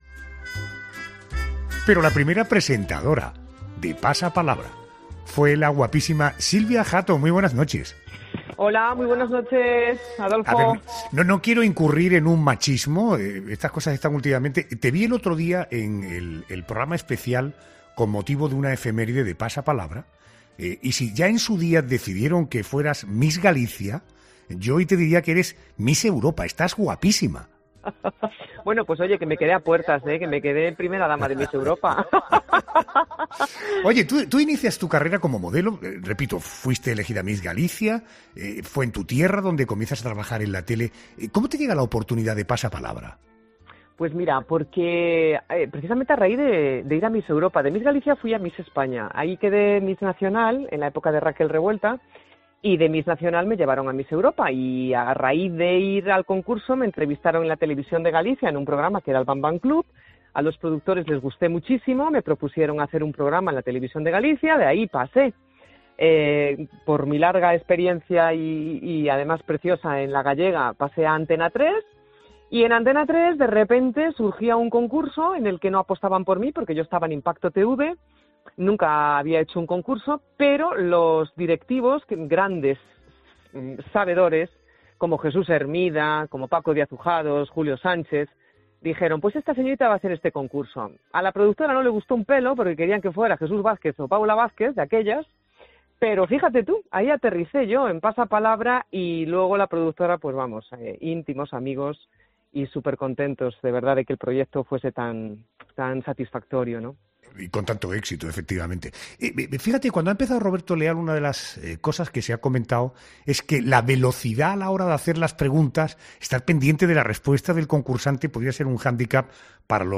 En 'La Noche de Adolfo Arjona' hablamos con Silvia Jato, primera presentadora del programa Pasapalabra en el año 2000